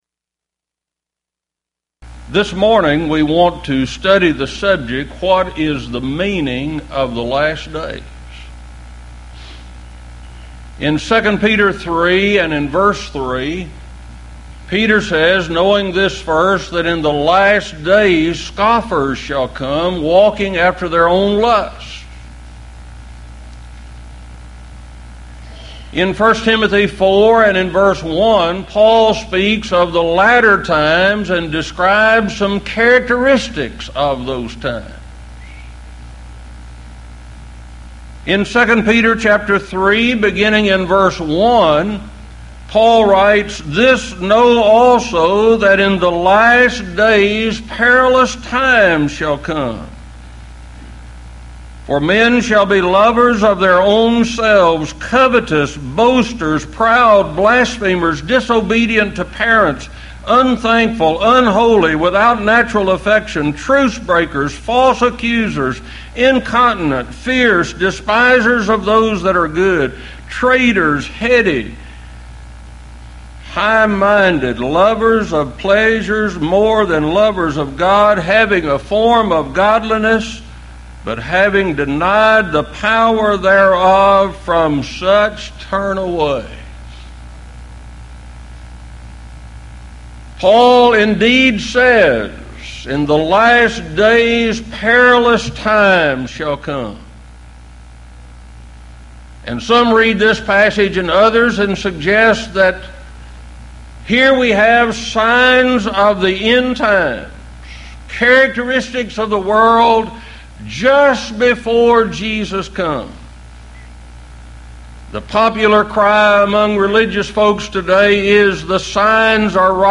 Series: Houston College of the Bible Lectures
this lecture